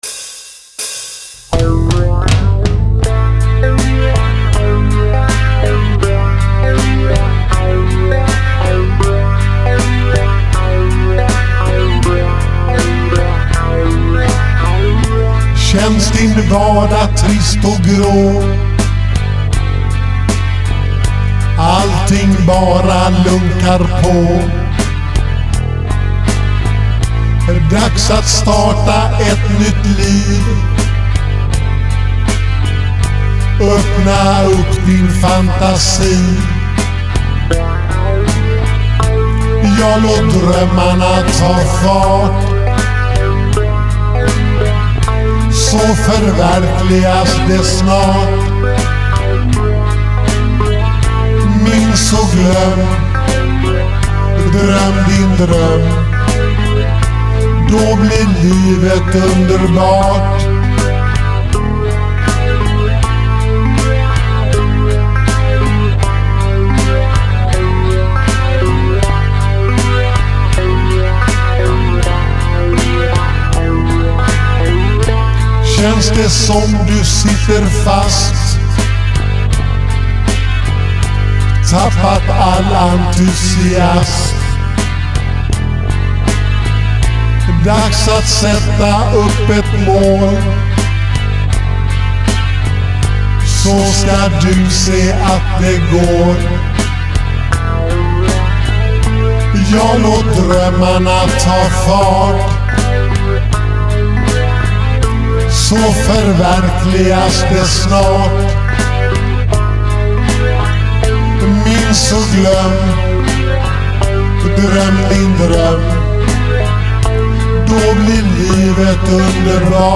Var mycket nöjd med riffen i låten.